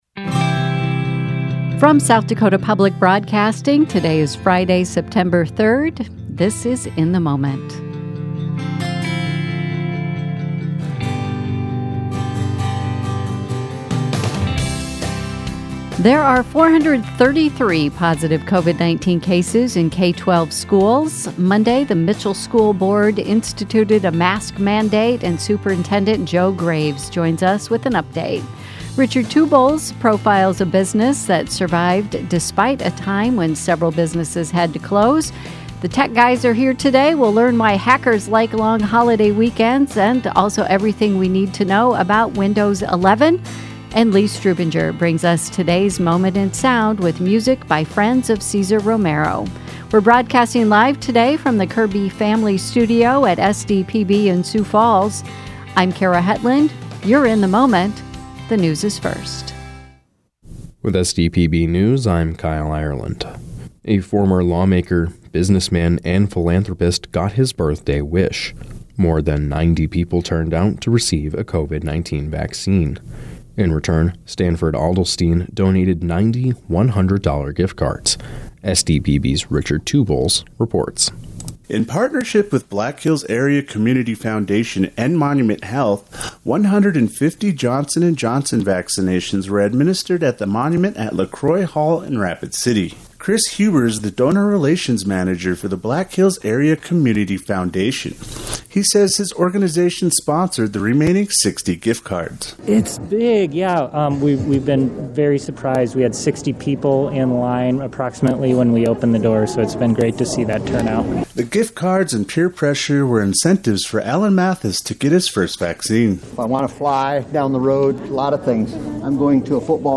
In the Moment is SDPB’s daily news and culture magazine program.